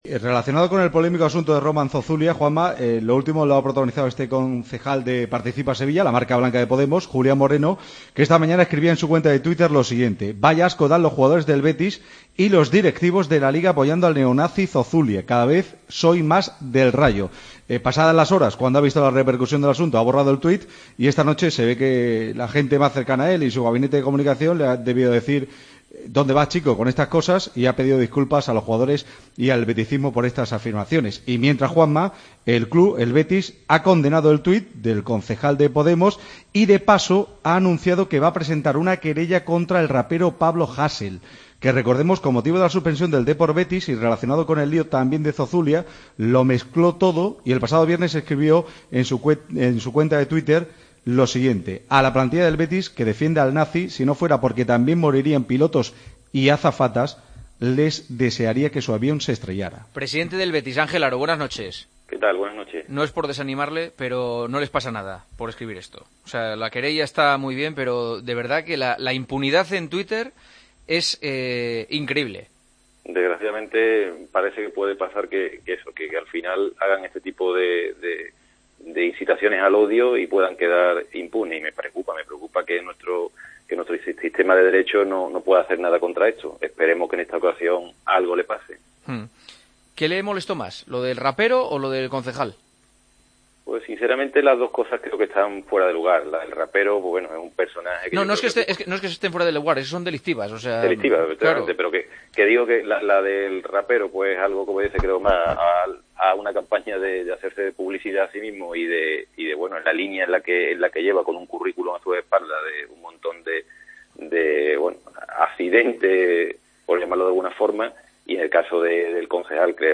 Hablamos con el presidente del Betis del polémico tweet del concejal de Podemos en Sevilla: "Me preocupa que nuestro sistema de derecho no pueda hacer nada contra esto.